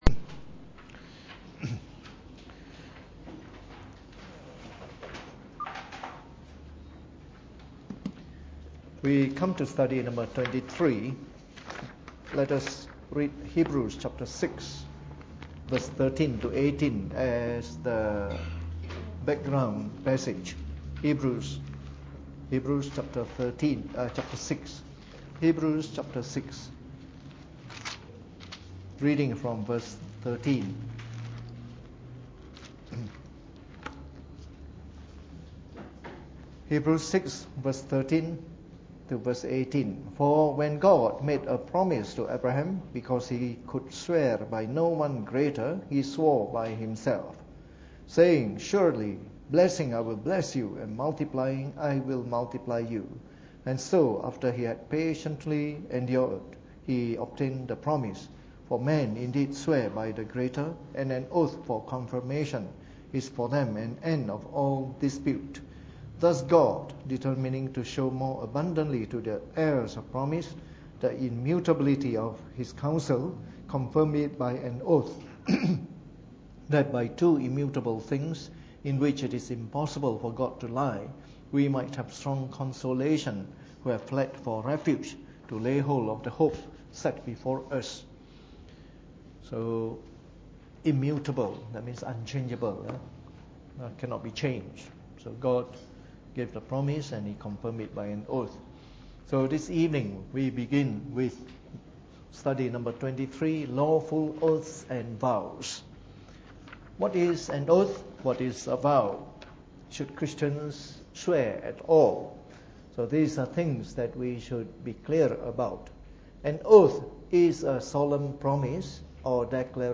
Preached on the 22nd of June 2016 during the Bible Study, from our series on the Fundamentals of the Faith (following the 1689 Confession of Faith).